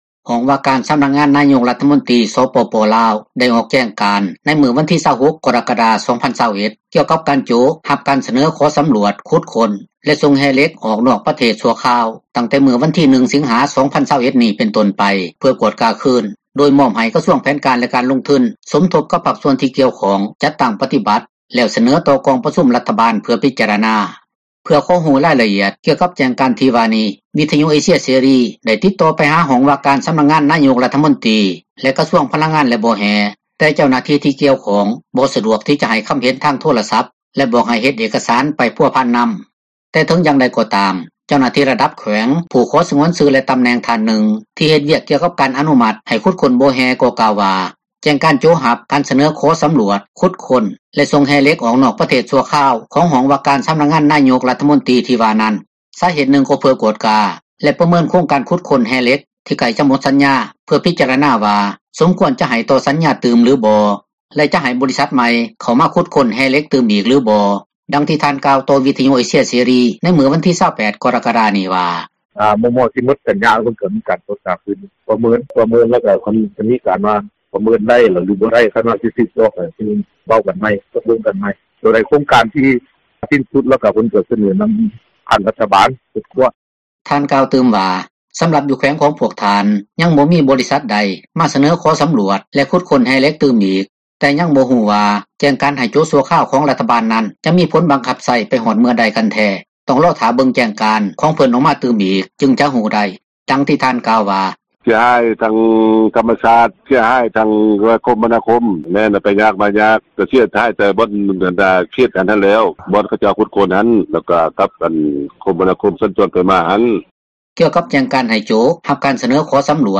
ແລະນັກວິຊາການດ້ານສິ່ງແວດລ້ອມ ນາງນຶ່ງກໍເວົ້າວ່າ ເຫັນດີນໍາແຈ້ງການນັ້ນ ແລະວ່າ ຖ້າເປັນໄປໄດ້ກໍຢາກໃຫ້ຣັຖບາລ ສັ່ງໂຈະ ການສໍາຣວດ, ຂຸດຄົ້ນ ແລະ ສົ່ງແຮ່ທຸກປະເພດ ອອກນອກປະເທດຊົ່ວຄາວ ເພື່ອສໍາຣວດຄືນ ຍ້ອນວ່າມີຫລາຍໂຄງການ ຂຸດຄົ້ນ ບໍ່ແຮ່ ໃຊ້ສານເຄມີຫຼາຍ ໃນການຂຸດຄົ້ນ ເຮັດໃຫ້ມີຄວາມສ່ຽງຕໍ່ ການທີ່ຈະເກີດຜົລກະທົບ ຕໍ່ສິ່ງແວດລ້ອມ ແລະ ພື້ນທີ່ທໍາການຜລິດ ຂອງປະຊາຊົນ ທີ່ຢູ່ໃກ້ຄຽງ ດັ່ງທີ່ນາງກ່າວວ່າ: